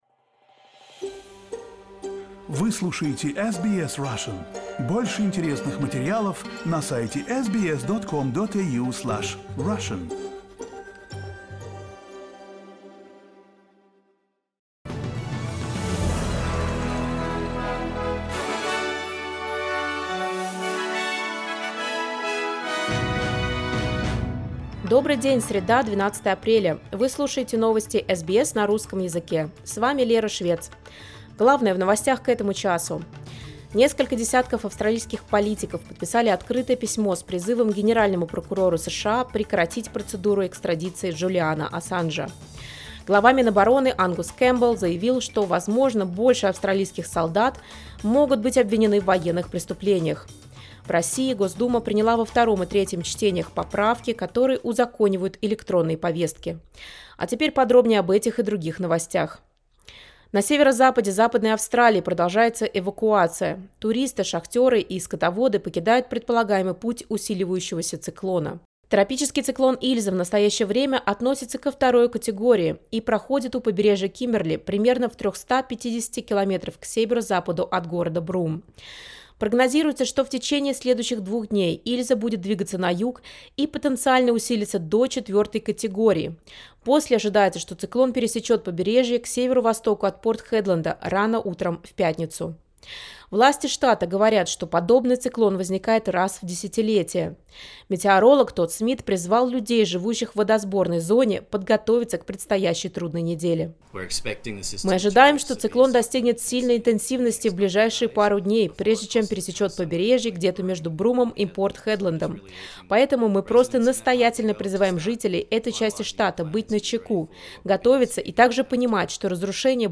SBS news in Russian — 12.04.2023